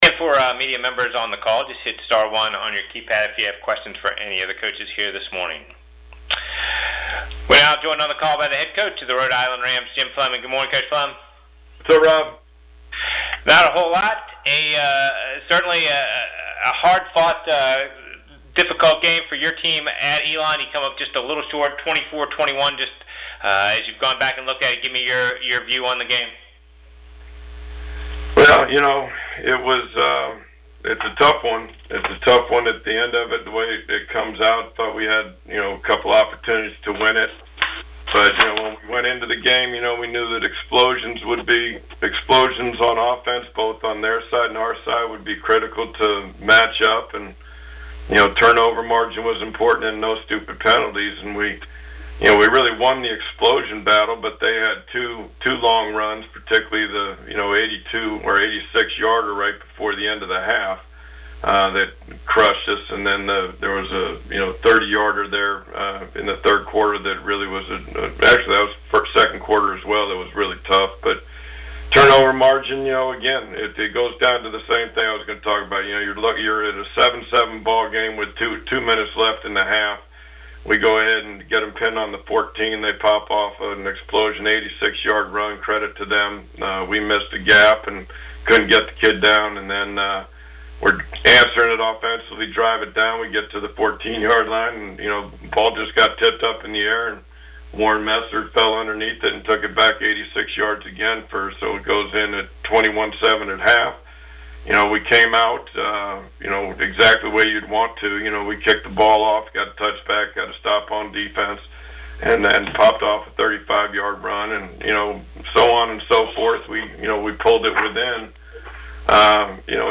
CAA Football Media Teleconference - Nov. 5
All 12 CAA football coaches participated in the league's weekly media teleconference on Monday. The coaches previewed their upcoming opponents and talked about last week's games.